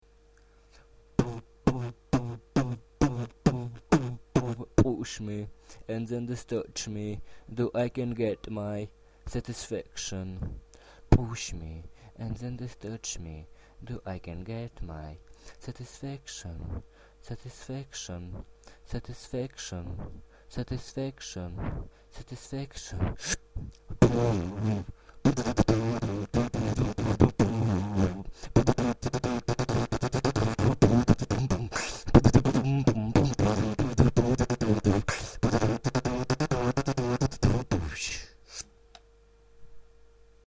аййй, много грязи в липе))